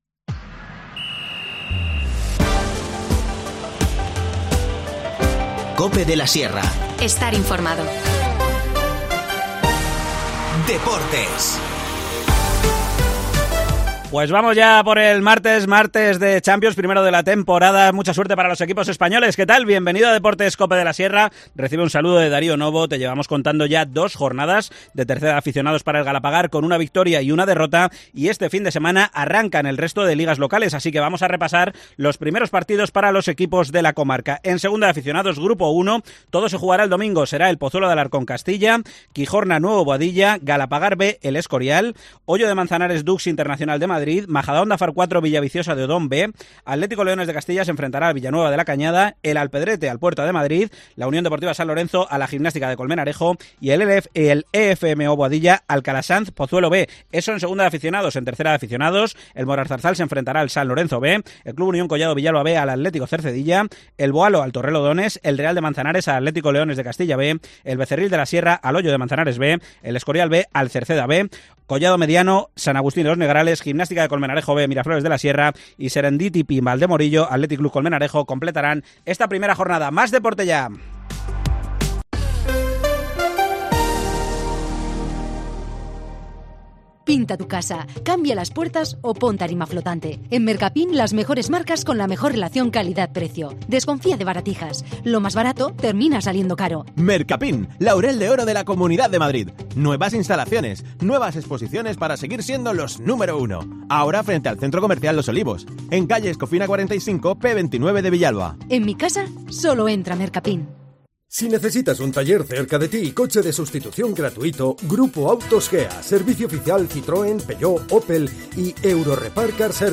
Este fin de semana han vuelto las carreras al Hipódromo de La Zarzuela y lo han hecho con tres dobletes. Nos lo cuenta desde allí